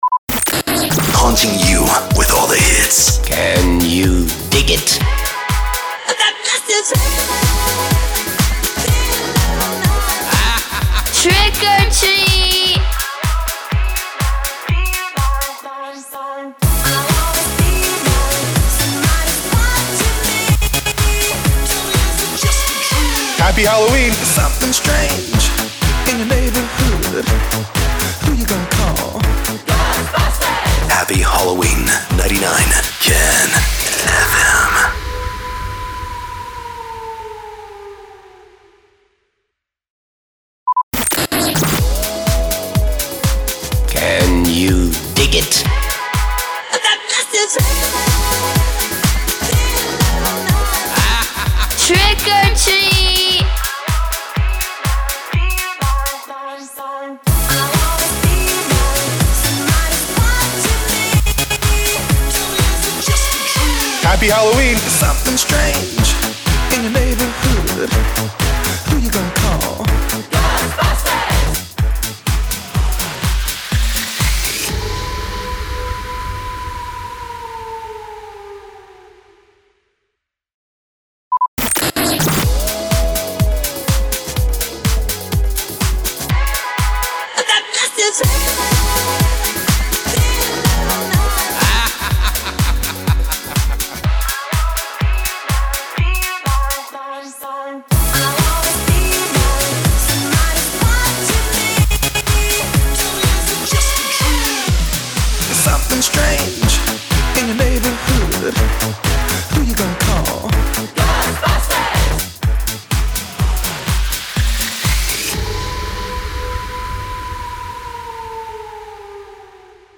543 – SWEEPER – HALLOWEEN BEATMIX PROMO